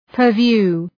Προφορά
{‘pɜ:rvju:}